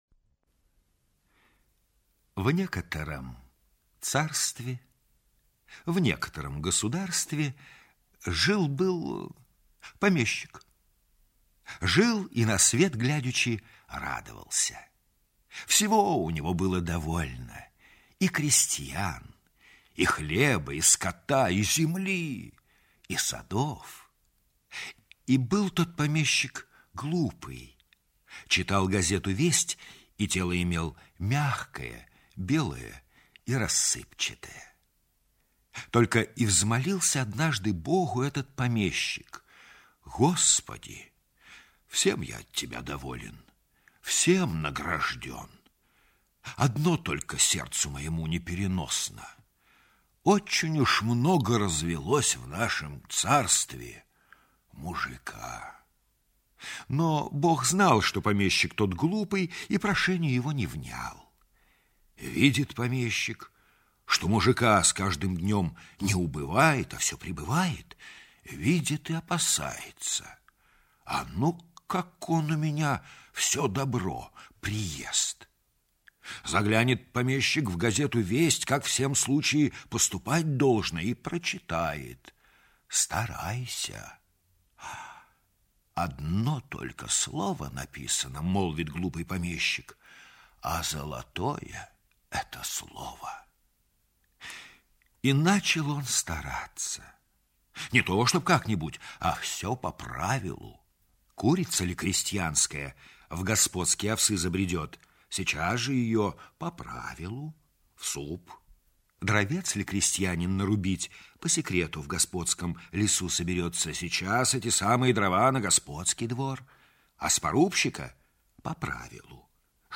Аудиокнига Дикий помещик
Качество озвучивания весьма высокое.